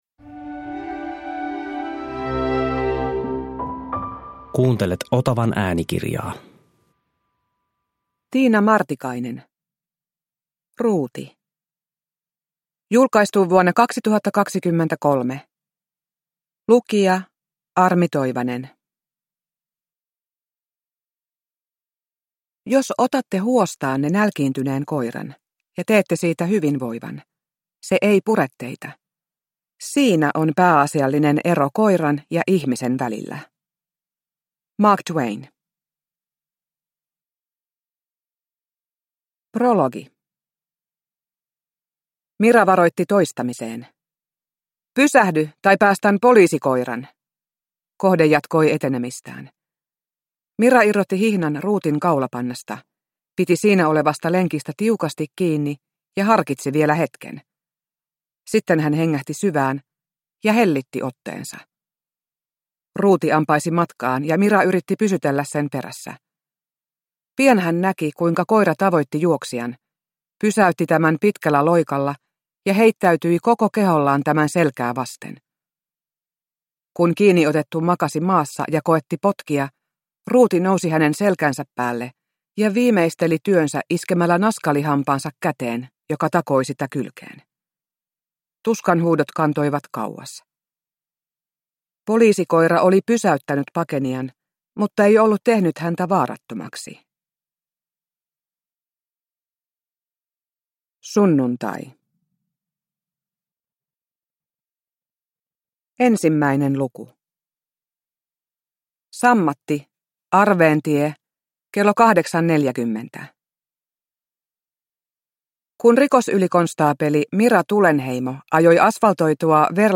Ruuti (ljudbok) av Tiina Martikainen